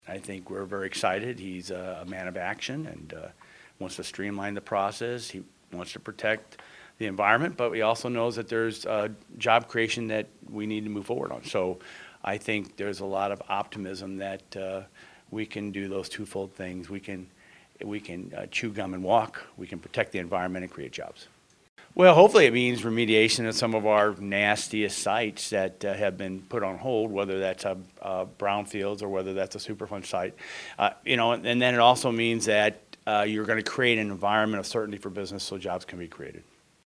May 3, 2017 - EPA Administrator Scott Pruitt's meeting with Rep. John Shimkus, Washington, DC.
Speaker: Rep. John Shimkus